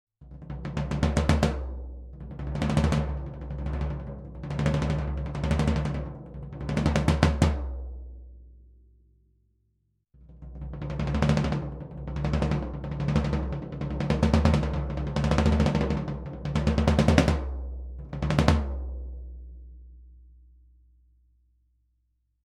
Das Standtom wurde wie im folgenden Bild zu sehen aufgebaut und mikrofoniert - zum Einsatz kamen zwei AKG C414B-ULS in einer ORTF-Anordnung, die direkt über ein Tascam DM24-Pult ohne weitere Bearbeitung mit 24 Bit in Samplitude aufgenommen wurden.
längere Rolls - erst Vollgummi-, dann Air-Suspension-Füße
Diese Audio-Files sind absolut unbearbeitet belassen worden - also kein EQ, kein Limiter, kein Kompressor - nichts!